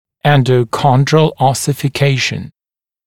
[ˌendə(u)ˈkɔndrəl ˌɔsɪfɪ’keɪʃn] [ˌэндо(у)ˈкондрэл ˌосифи’кейшн] эндохондральная оссификация